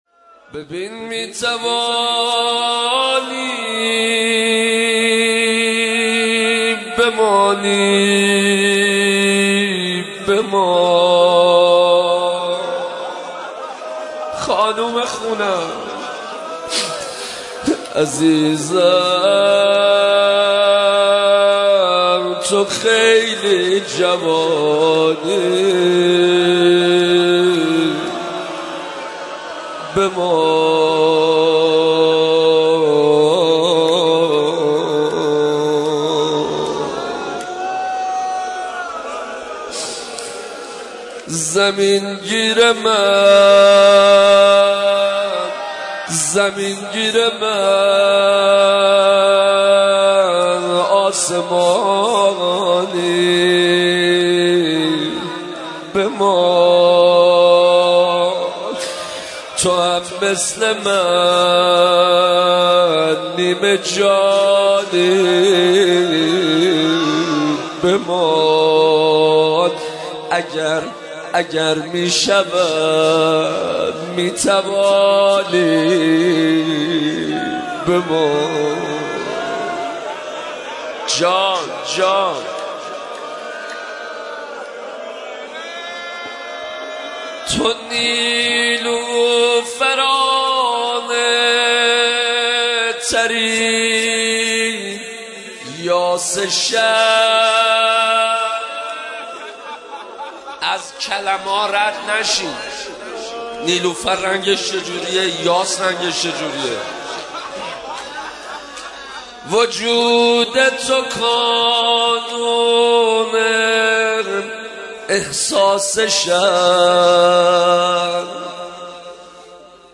مداحی و نوحه
روضه خوانی
به مناسبت ایام فاطمیه